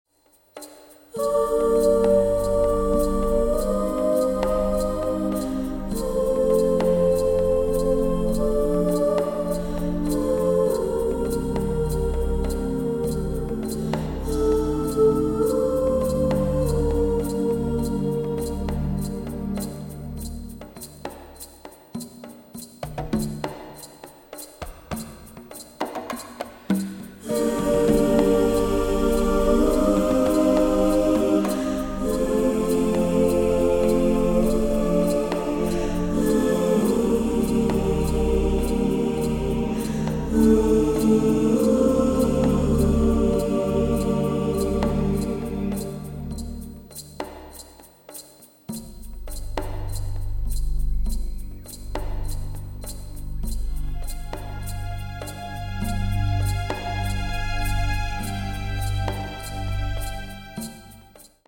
primarily synths
strings, winds, and heavy percussion